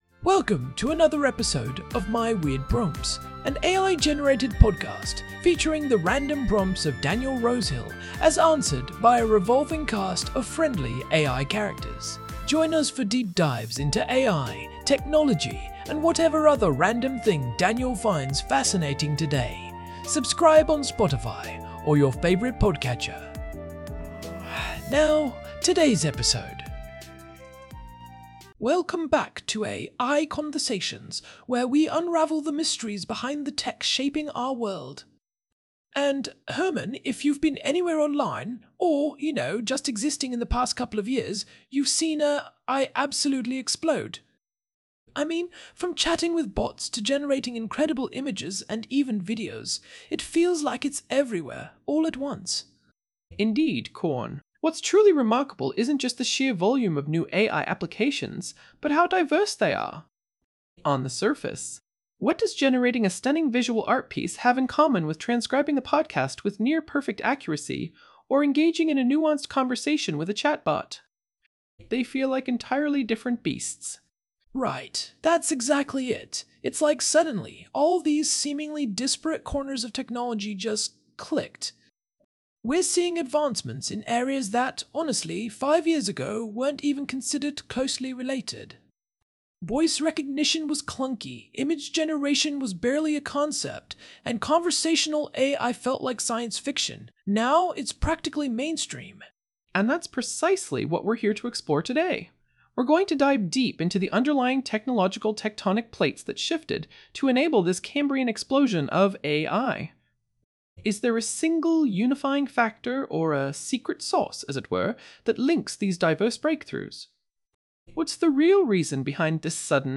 AI-Generated Content: This podcast is created using AI personas.
TTS Engine chatterbox-tts
Hosts Herman and Corn are AI personalities.